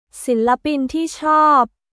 シラピン・ティー・チョープ